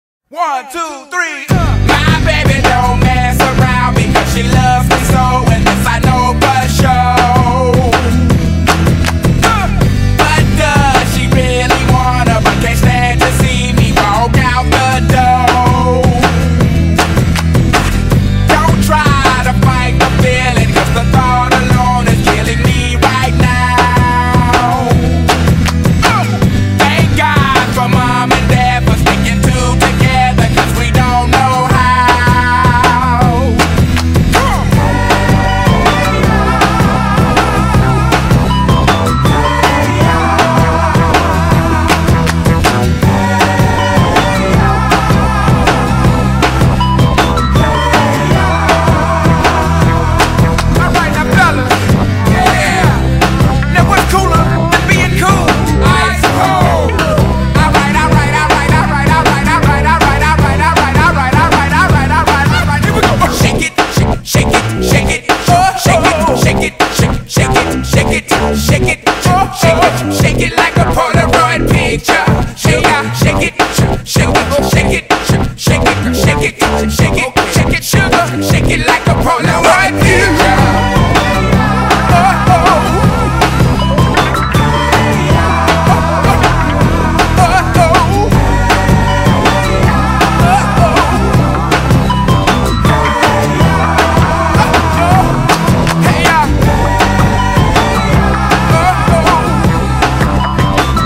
BPM160-160
Audio QualityMusic Cut